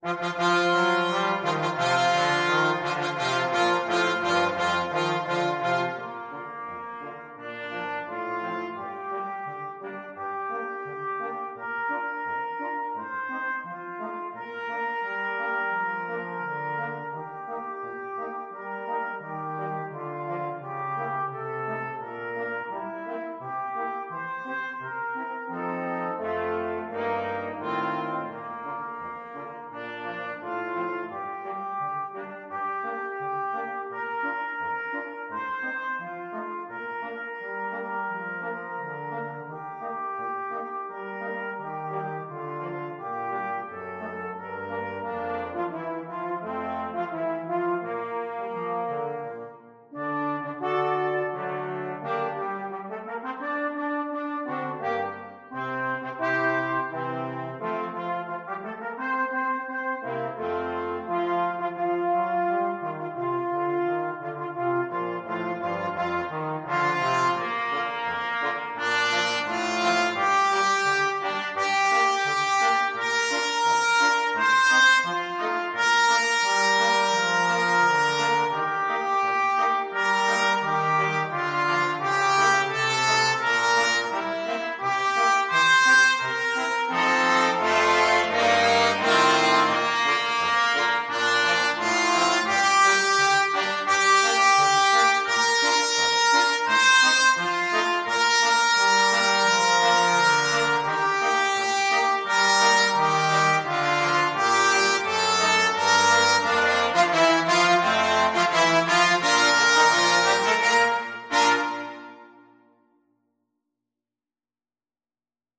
Trumpet 1Trumpet 2French HornTrombone
March Tempo - Moderato = c.86
2/2 (View more 2/2 Music)